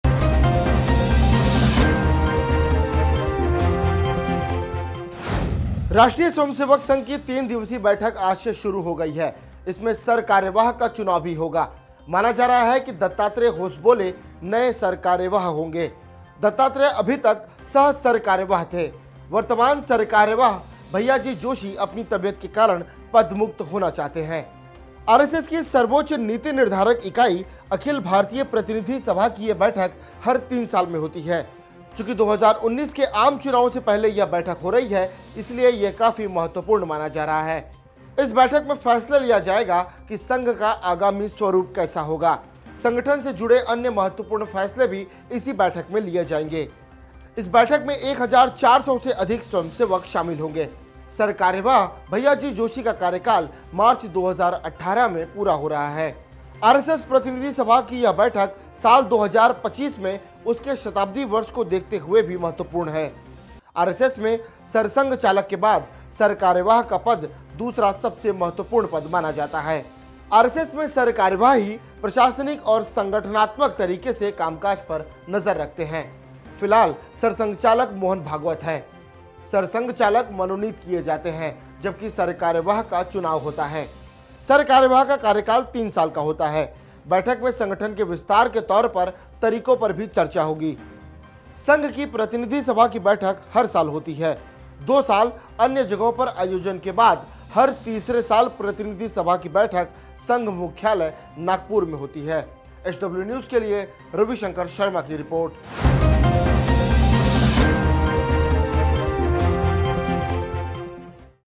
News Report / भैय्याजी जोशी की जगह दत्तात्रेय होसबोले हो सकते हैं RSS के नए सरकार्यवाह